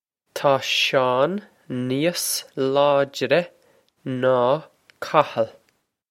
Taw Shawn nee-oss loyd-reh naw Kah-hul.
This is an approximate phonetic pronunciation of the phrase.